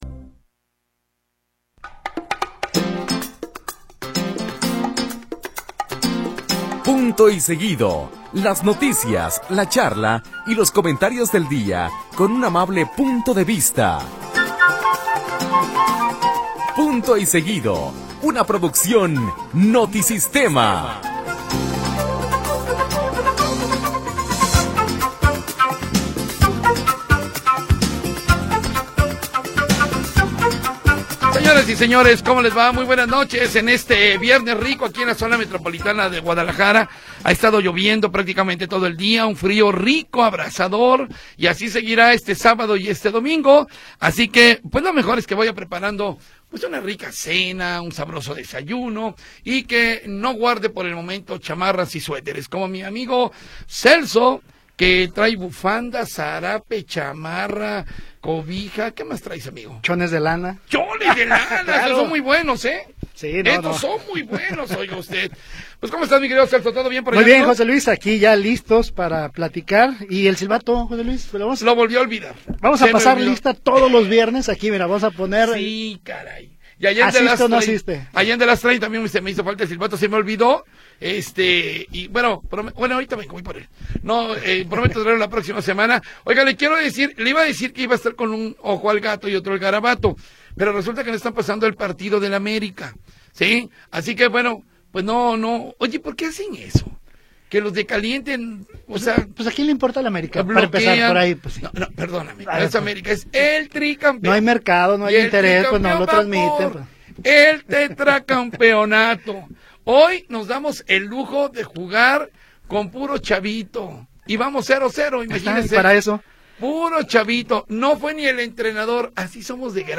… continue reading 26 episodes # Notisistema # Ondas De Alegria # Unidifusion # Guadalajara # Discusión de Noticias # Países Bajos Noticias # JALISCO